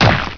monsters / harpy / attack.wav
attack.wav